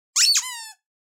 The toy is squeezed in the hands and it squeaks
• Category: A dog toy (squeaker)
• Quality: High